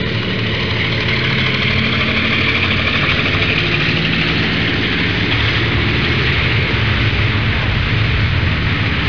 دانلود آهنگ طیاره 61 از افکت صوتی حمل و نقل
دانلود صدای طیاره 61 از ساعد نیوز با لینک مستقیم و کیفیت بالا
جلوه های صوتی